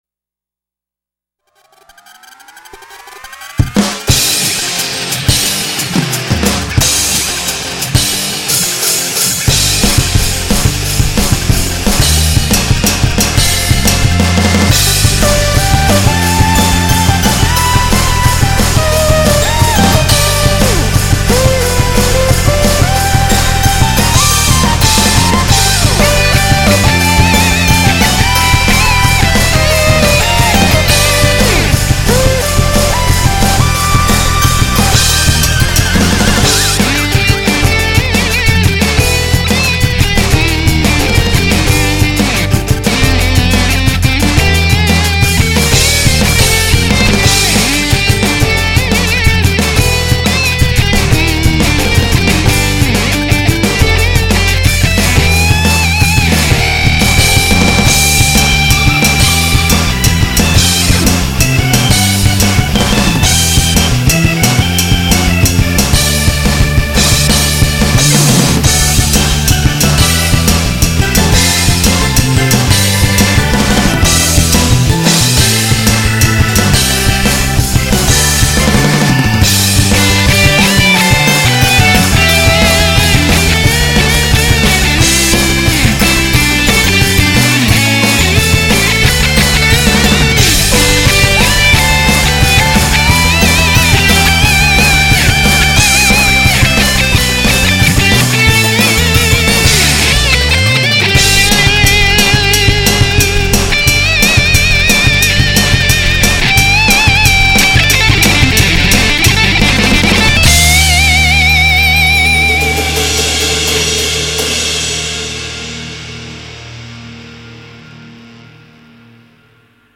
몽환적인 퓨젼